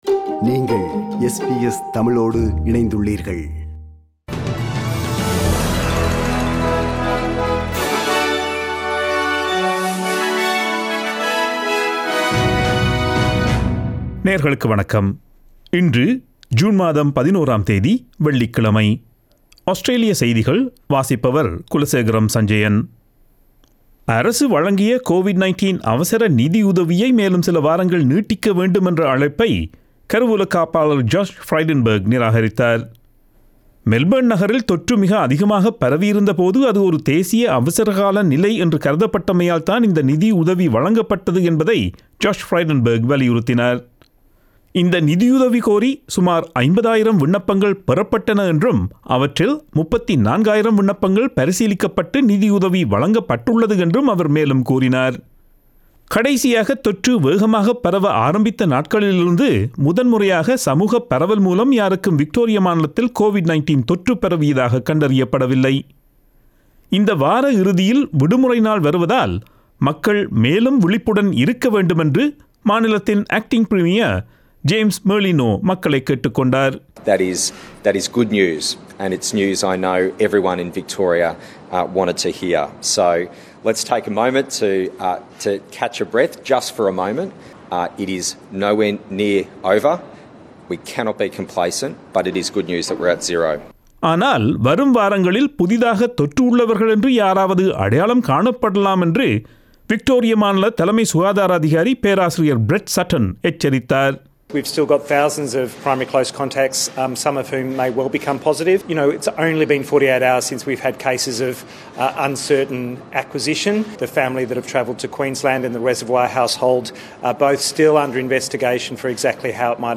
Australian news bulletin for Friday 11 June 2021.